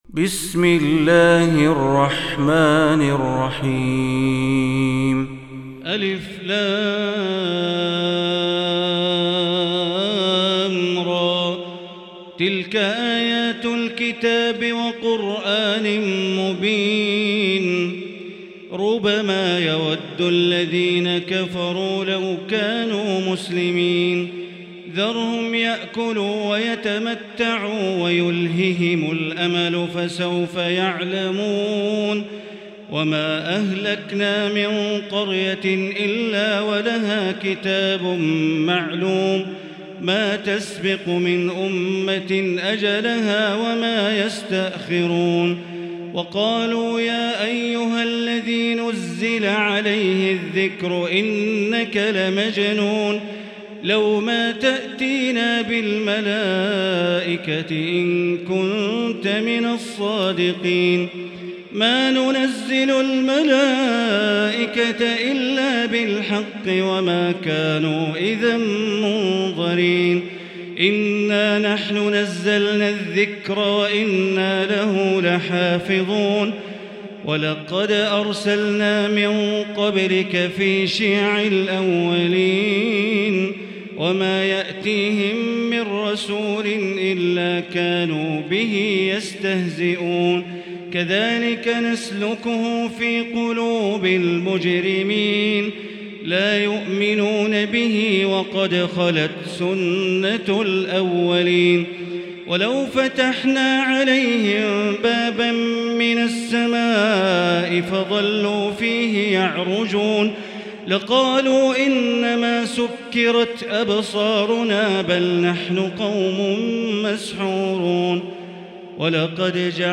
المكان: المسجد الحرام الشيخ: معالي الشيخ أ.د. بندر بليلة معالي الشيخ أ.د. بندر بليلة الحجر